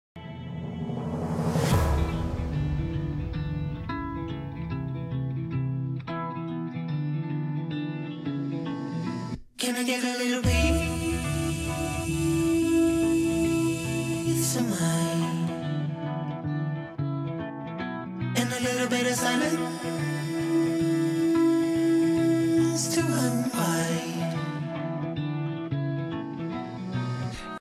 drone.ogg